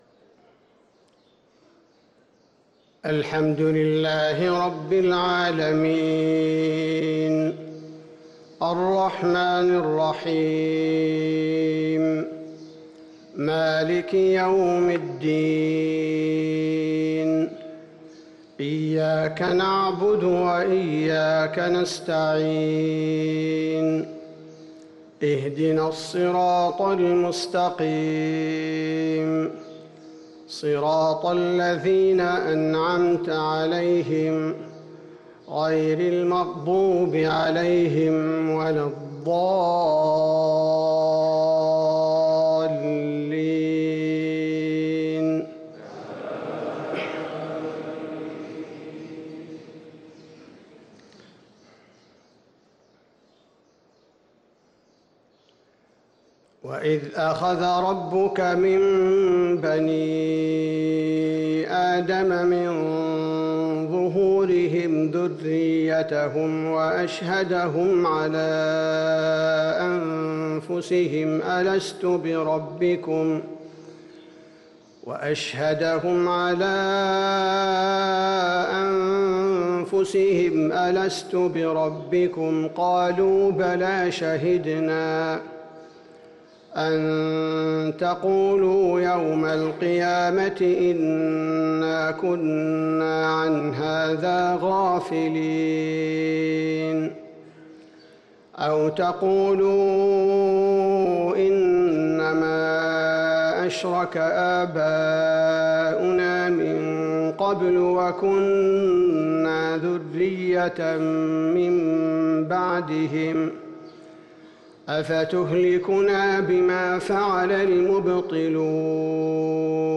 صلاة الفجر للقارئ عبدالباري الثبيتي 6 جمادي الأول 1444 هـ